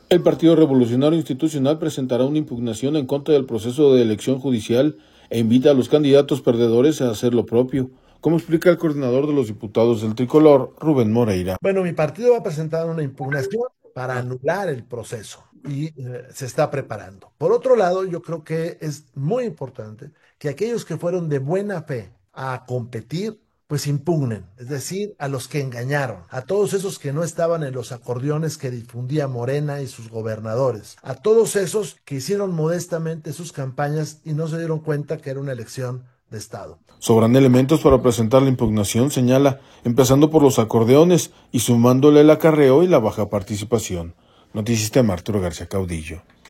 El Partido Revolucionario Institucional presentará una impugnación en contra del proceso de elección judicial e invita a los candidatos perdedores a hacer lo propio, como explica el coordinador de los diputados del Tricolor, Rubén Moreira.